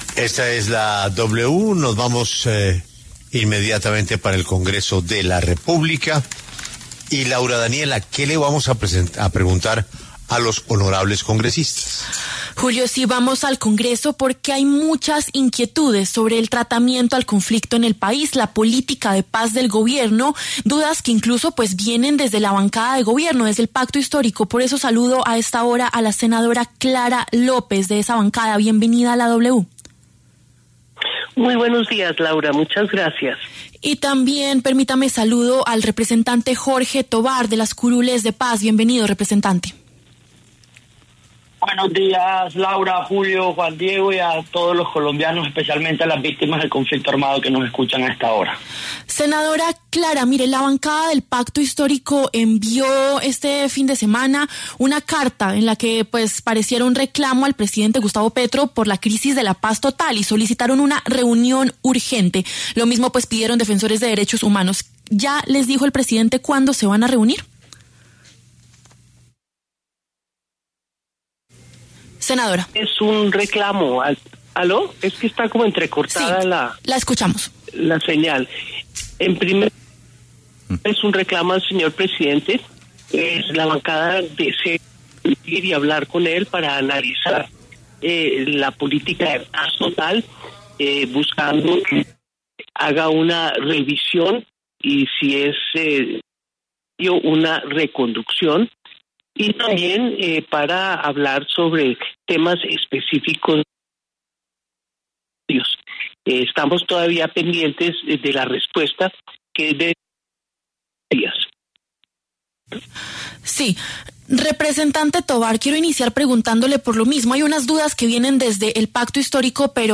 La senadora Clara López, del Pacto Histórico, y el representante Jorge Tovar, de las curules de paz, pasaron por los micrófonos de La W.